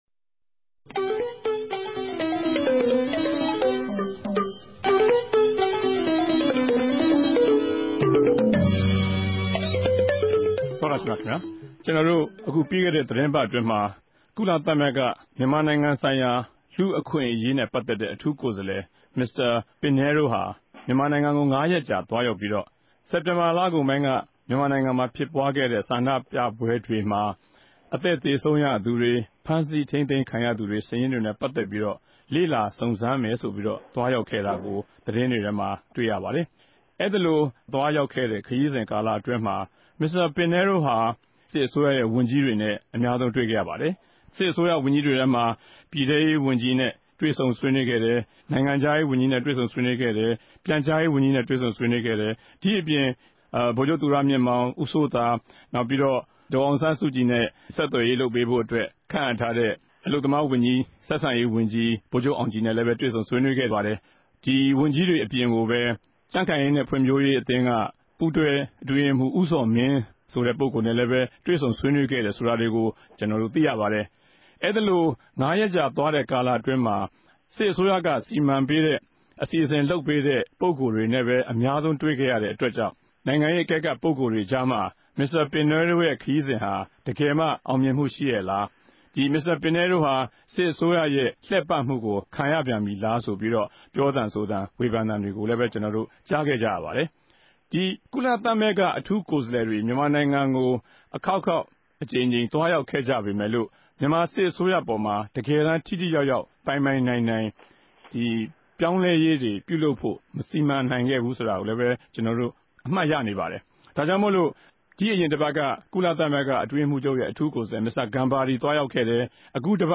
ဒီတပတ် တနဂဿေိံြဆြေးေိံြးပြဲ စကားဝိုင်းမြာ ္ဘပီးခဲ့တဲ့ သီတင်းပတ်အတြင်း ကုလ သမဂ္ဂ လူႚအခြင့်အရေးဆိုင်ရာ အထူးကိုယ်စားလည် မင်္စတာ ပင်နဲရိုးရဲႚ ူမန်မာိံိုင်ငံခရီးစဉ်အပေၞ ဘယ်လောက် အောင်ူမင်မြ ရြိတယ် မရြိဘူး၊ အကဵိြး ူဖစ်တယ် မူဖစ်ဘူးဆိုတာတေကြို ဒီအစီအစဉ်မြာ သုံးသပ်တင်ူပထားပၝတယ်၊၊